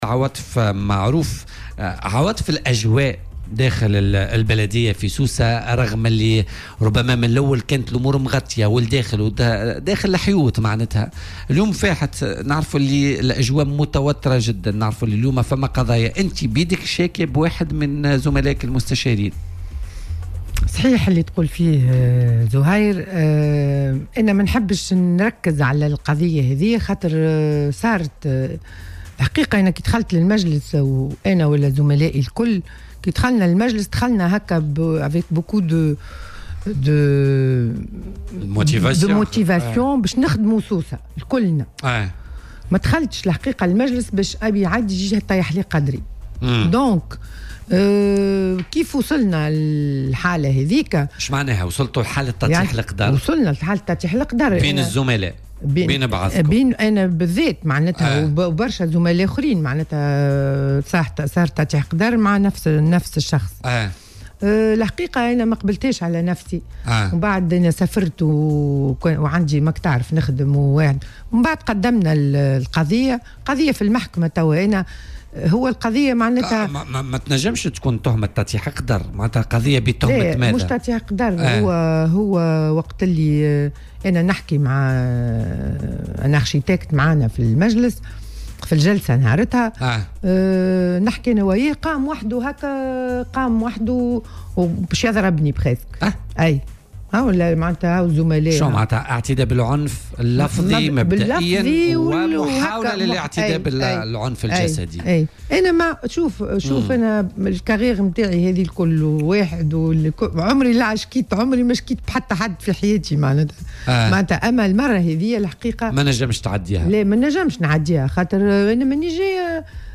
وقالت ضيفة "بوليتيكا" على "الجوهرة أف أم" إنها تقدمت بشكوى قضائية ضد رئيس لجنة الرياضة أكرم اللقام، بتهمة التعرّض للعنف اللفظي مع التهديد بالضّرب، مشيرة إلى أنه وصلتها أيضا تهديدات على مواقع التواصل الاجتماعي.